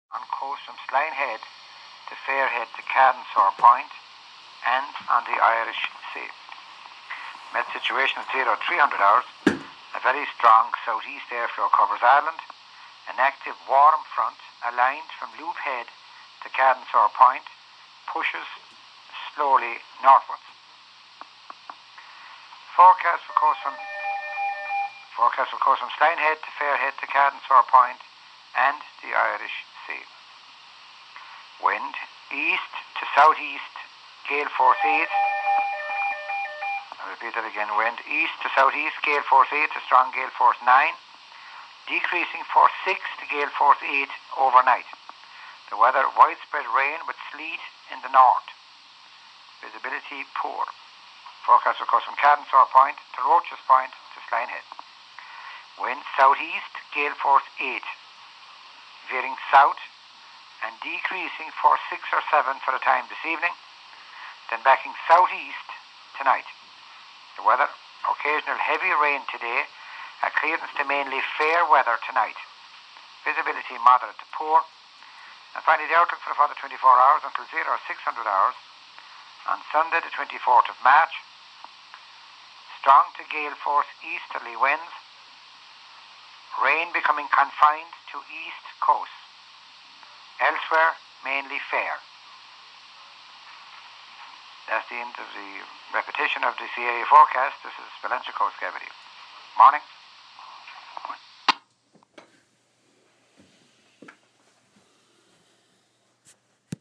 Sea area forecast Friday Mar 22 VHF